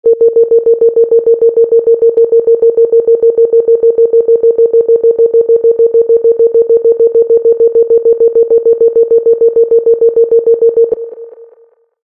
Beep ringtone free download
Sound Effects